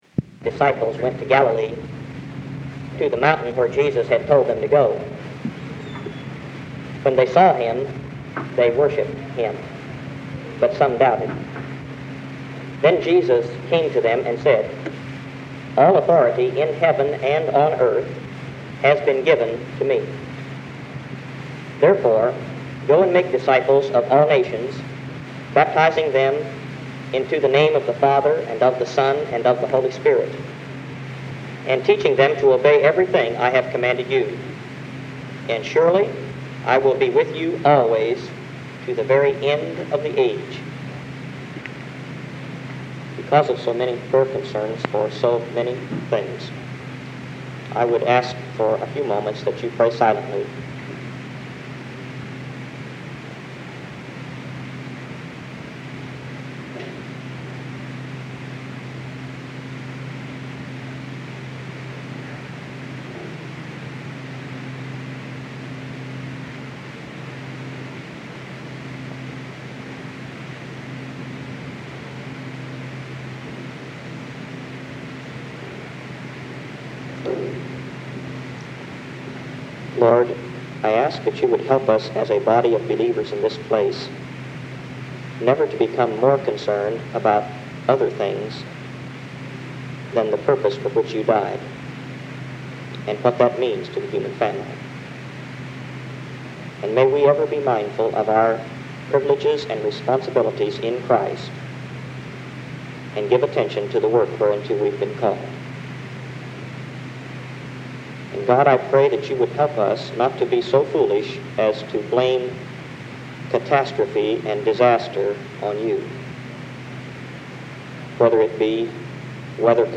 The audio from this cassette tape is not great quality. There are speed changes and eventually it just ended early. But most of the sermon is audible.